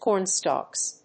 /ˈkɔˌrnstɔks(米国英語), ˈkɔ:ˌrnstɔ:ks(英国英語)/